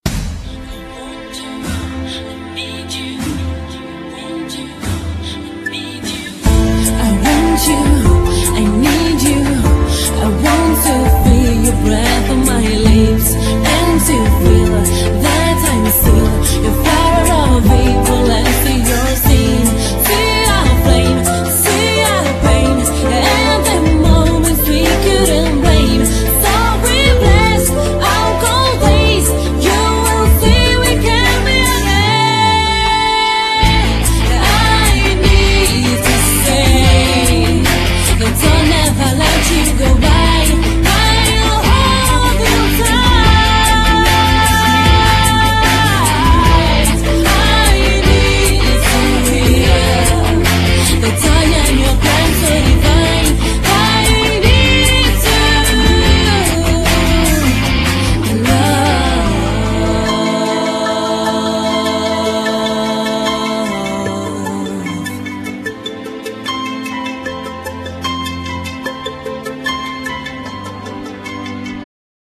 Genere : Pop / rock